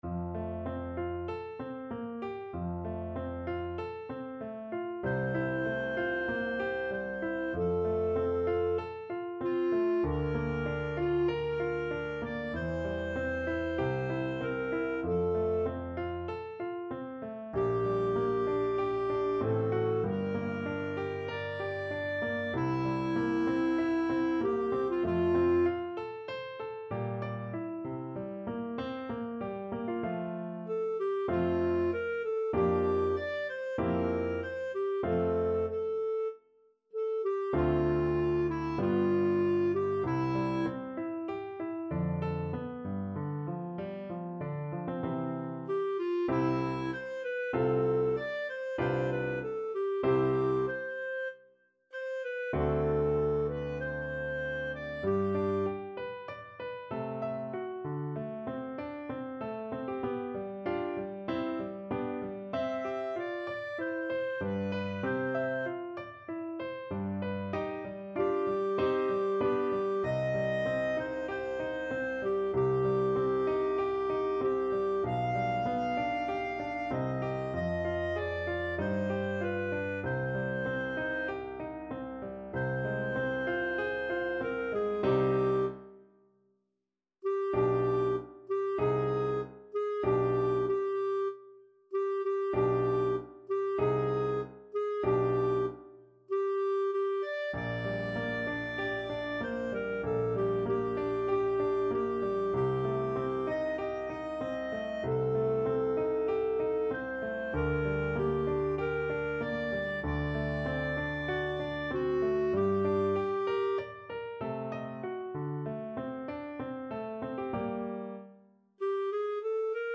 Classical Mozart, Wolfgang Amadeus Abendempfindung an Laura, K.523 Clarinet version
F major (Sounding Pitch) G major (Clarinet in Bb) (View more F major Music for Clarinet )
~ = 96 Andante
4/4 (View more 4/4 Music)
Clarinet  (View more Easy Clarinet Music)
Classical (View more Classical Clarinet Music)